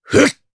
Riheet-Vox_Attack1_jp.wav